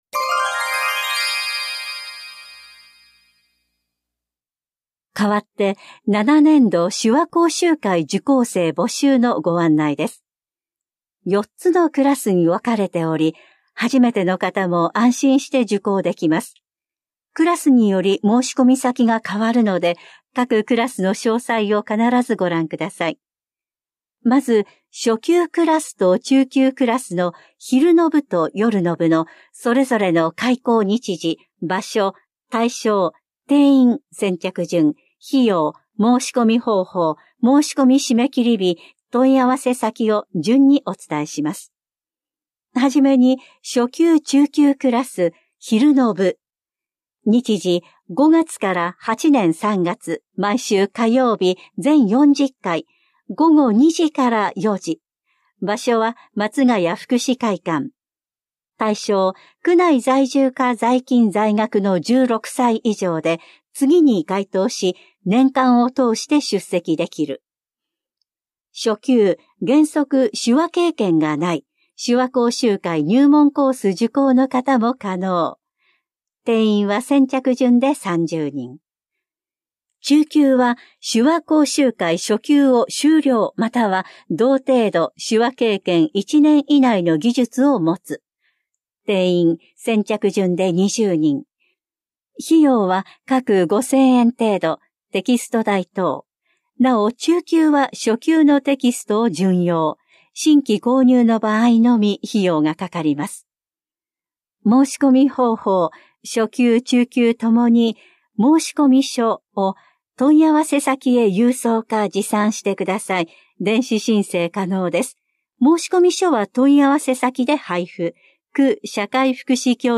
広報「たいとう」令和7年2月20日号の音声読み上げデータです。